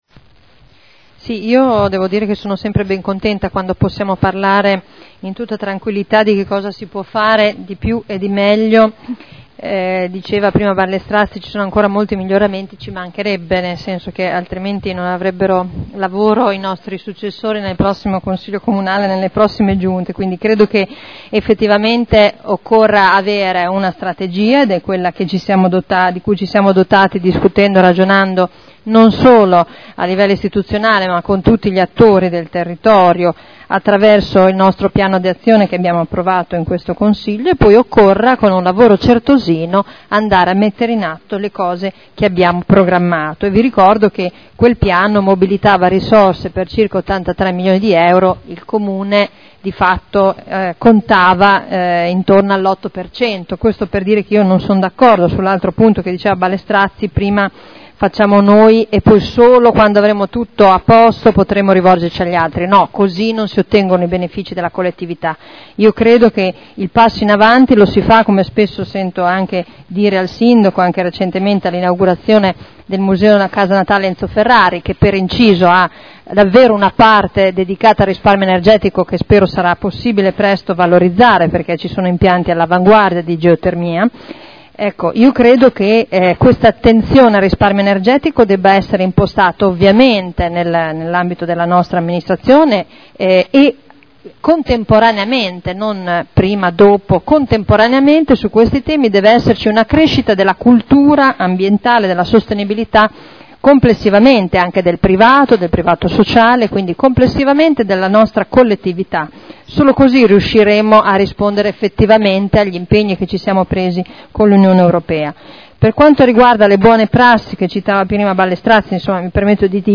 Seduta del 12/03/2012. Conclusione a dibattito su interrogazione dei consiglieri Ricci (Sinistra per Modena) e Trande (P.D.) avente per oggetto: “Risparmio energetico” – Primo firmatario consigliere Ricci (presentata l’11 gennaio 2012 - in trattazione il 12.3.2012).